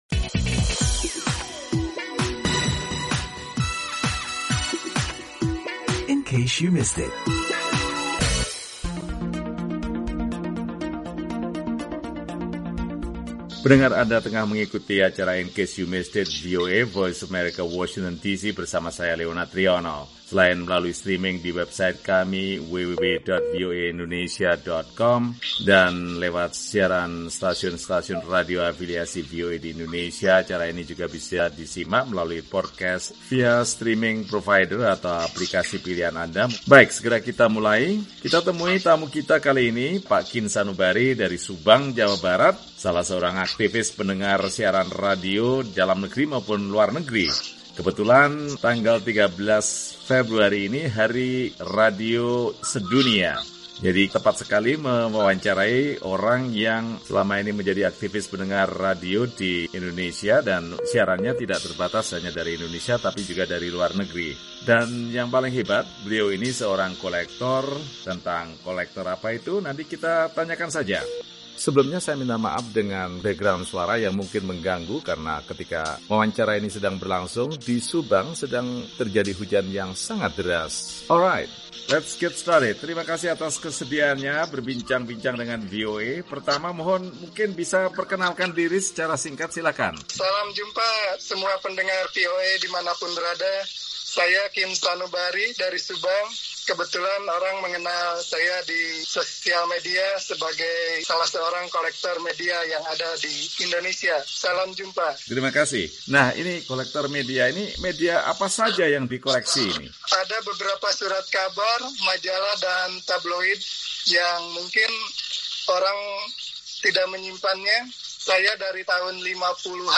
Ngobrol Dengan Kolektor Koran dan Majalah